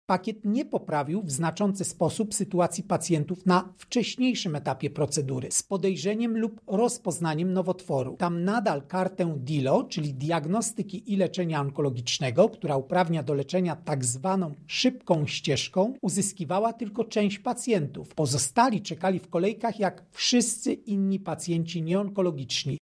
Są też plusy wprowadzenia pakietu onkologicznego. Jak dodaje prezes NIKu, Krzysztof Kwiatkowski, pacjenci posiadający już specjalną kartę na zabieg czekali krócej.